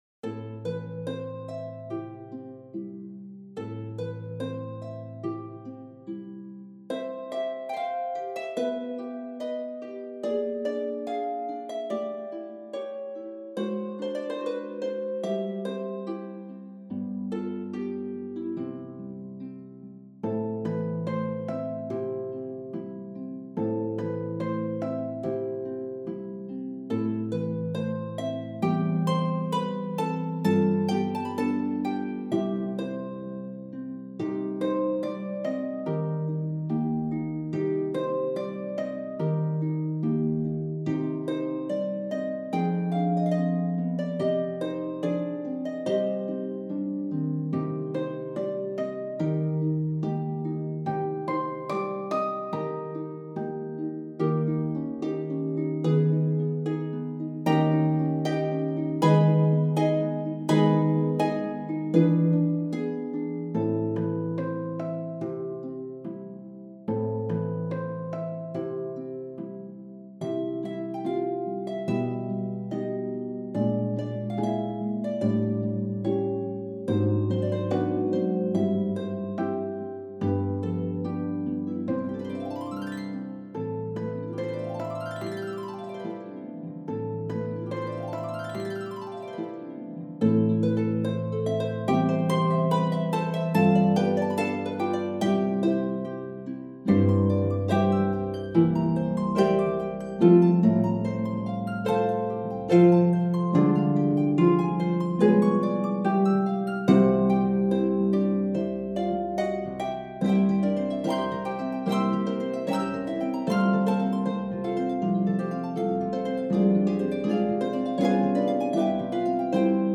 Op. 98 is for four pedal harps.